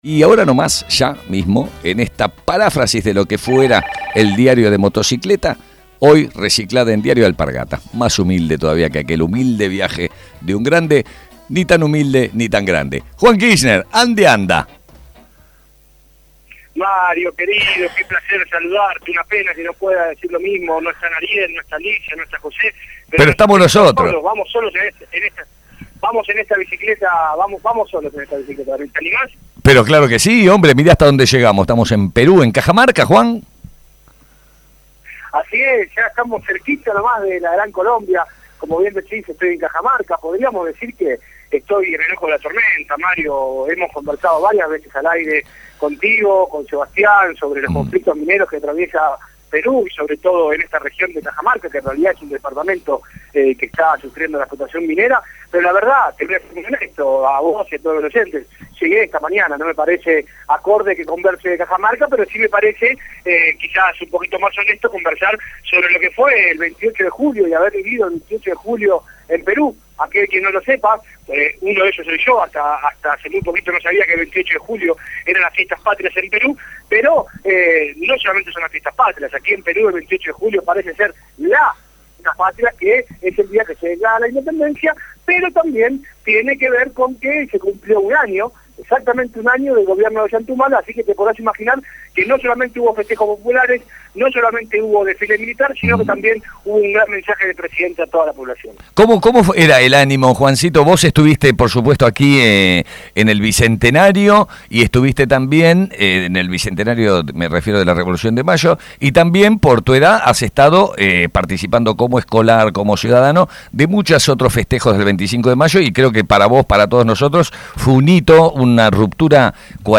DESDE CAJAMARCA, PERU